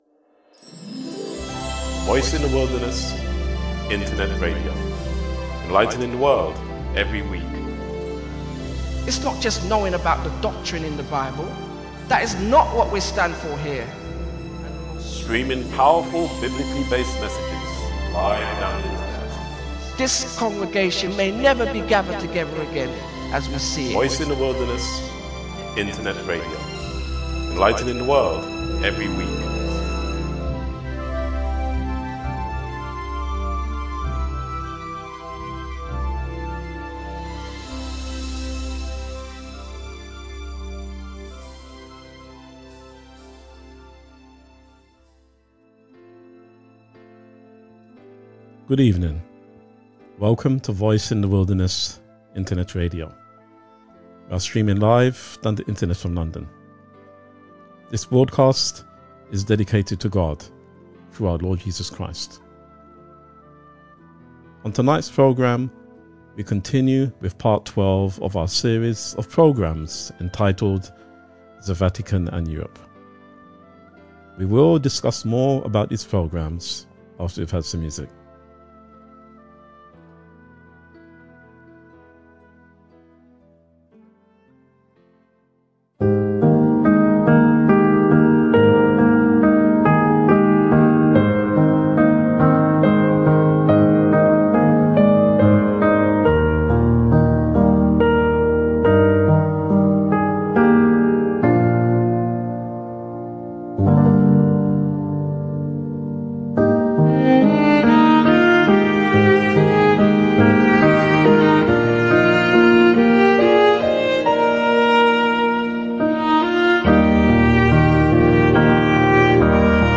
Made In Bondi & Byron Baes 58:52 Play Pause 21h ago 58:52 Play Pause Play later Play later Lists Like Liked 58:52 This is Beyond Reality, the podcast that explores the world of television production by chatting to the people behind the tv shows you love.